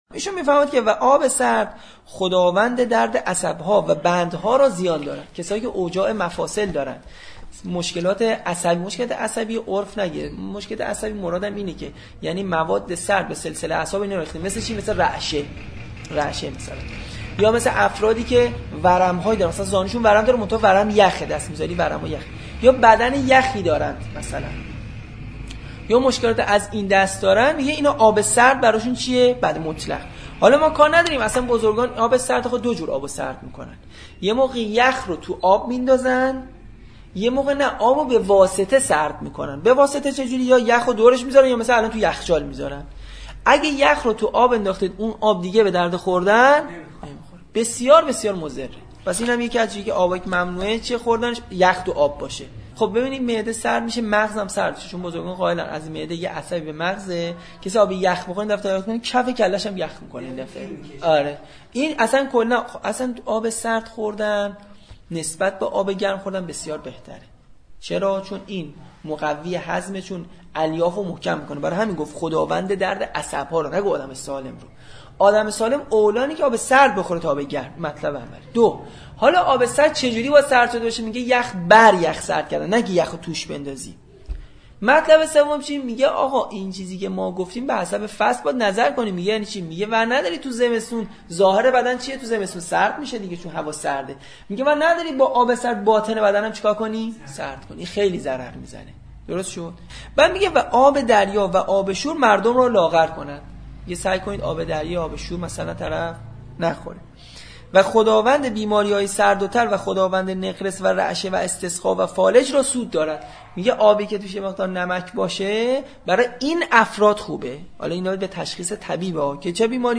فایل صوتی با توضیحات بسیار زیبا ی دکتر طب سنتی